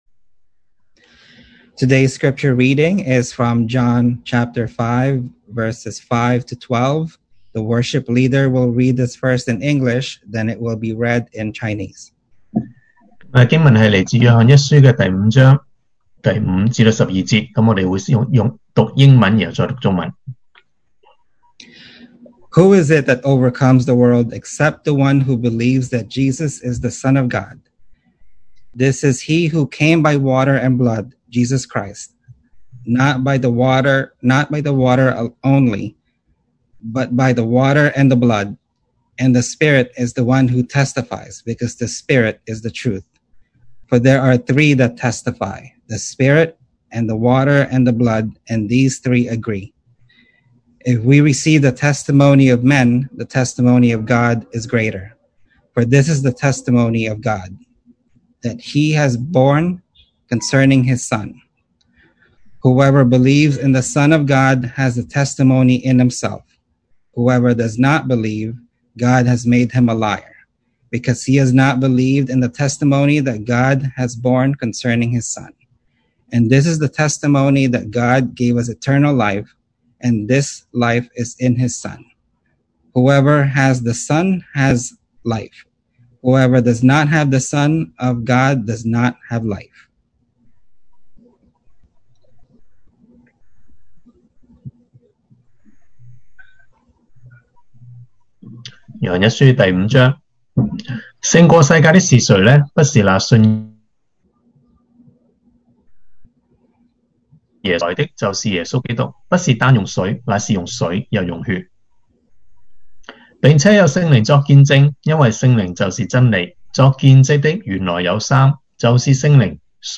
Series: 2020 sermon audios
Service Type: Sunday Morning